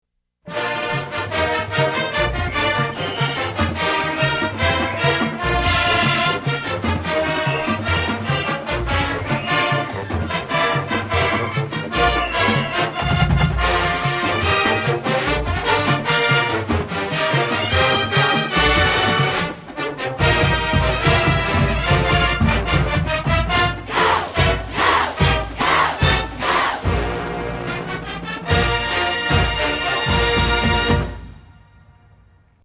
Songs performed by the Redcoat Marching Band